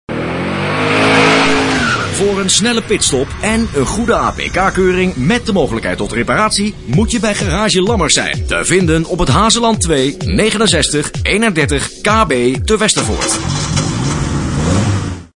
Commercials
Op deze pagina tref je een aantal voorbeelden aan van commercials die de afgelopen tijd door mij zijn ingesproken voor diverse lokale-, regionale- en interrnet-radiostations.